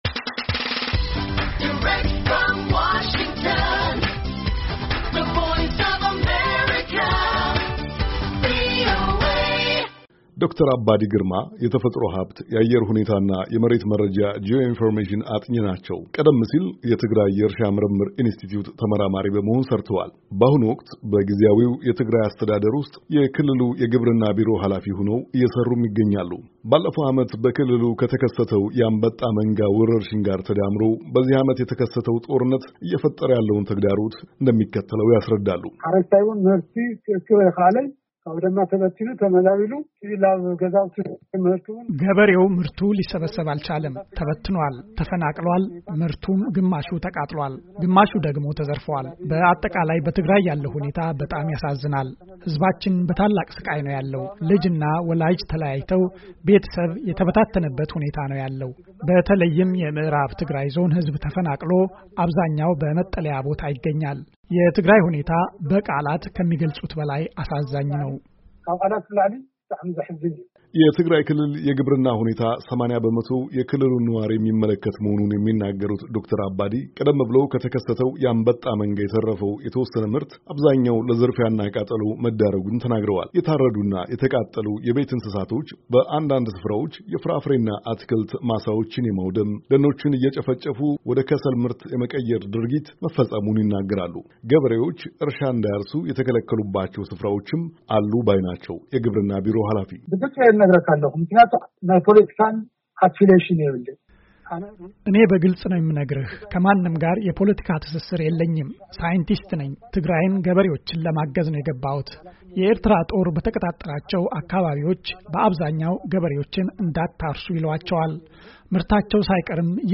ለዚህ መልስ የሚሰጡንን የትግራይ ክልል ጊዜያዊ መስተዳድር የግብርና ቢሮ ኃላፊ ዶክተር አባዲ ግርማይ ናቸው።